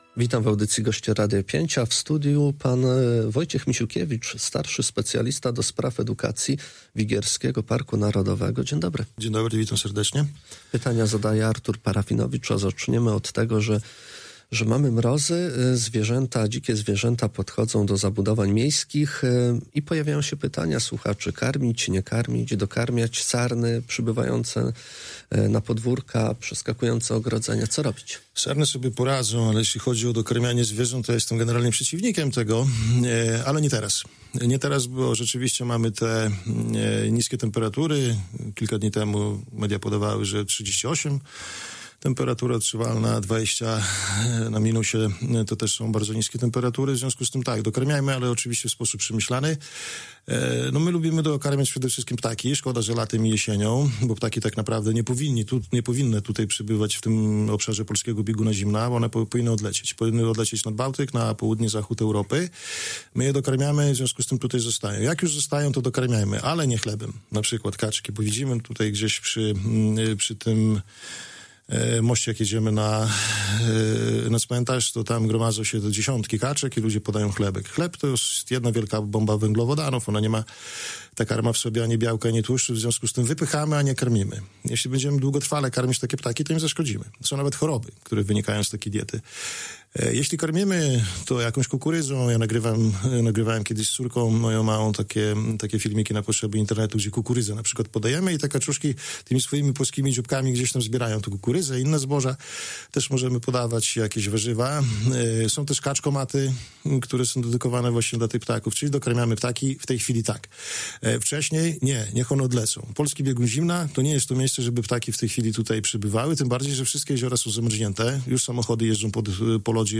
W związku sygnałami o licznych wizytach dzikich zwierząt na terenach miejskich zaprosiliśmy eksperta, aby udzielił fachowych porad dotyczących postępowania w takich sytuacjach.